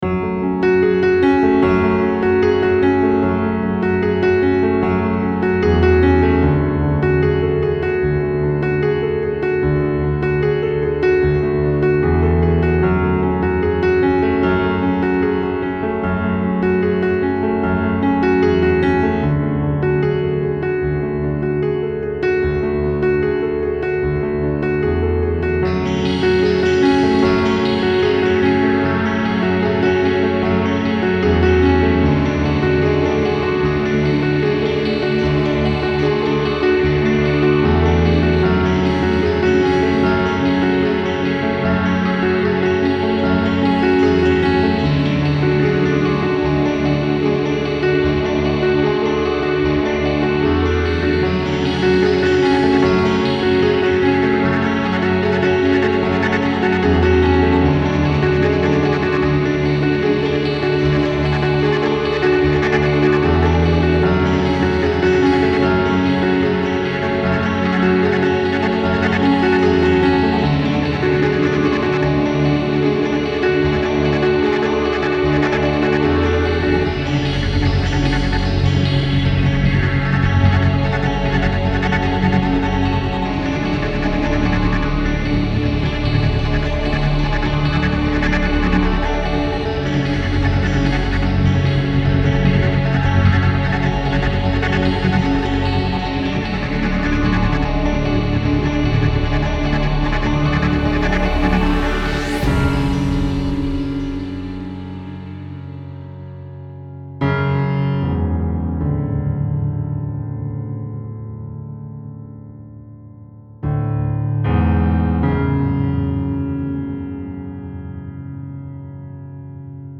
Style Style Ambient
Mood Mood Calming, Mysterious
Featured Featured Bass, Piano, Synth
BPM BPM 150
An otherworldly, ambient piano track.